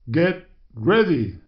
voice_get_ready.wav